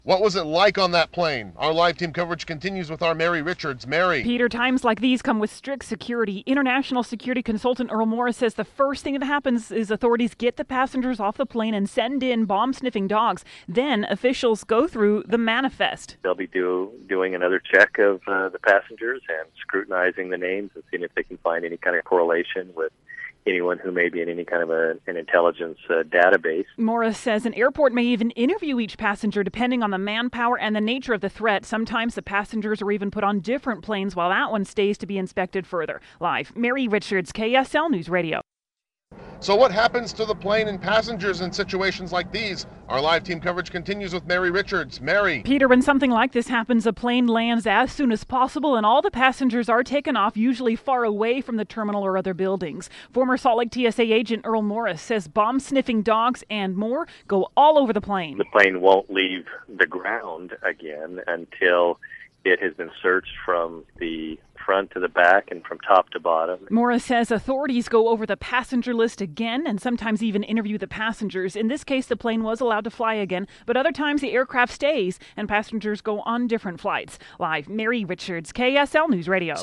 Security expert talks about what happens on threatened flights